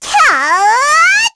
Lilia-Vox_Casting3_kr.wav